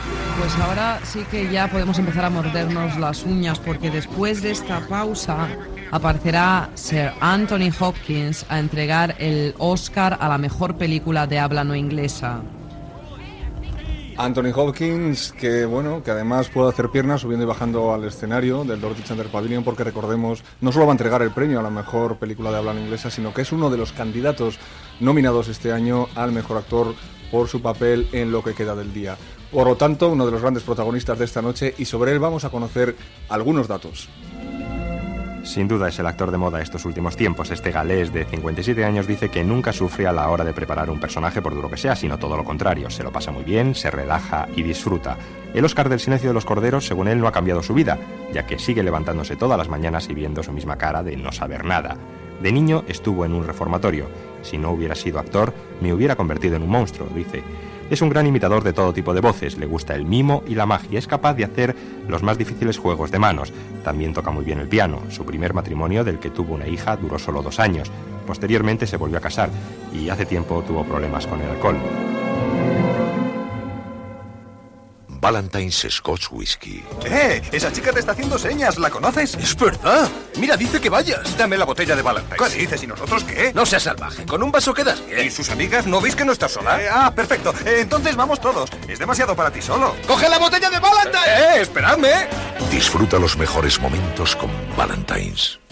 Espai especial per informar, des del Dorothy Chandler Pavilion de Los Angeles, de la cerimònia de lliurament dels premis Oscar de cinema.
Fragment de l'Oscar a la millor pel·lícula no anglesa i publicitat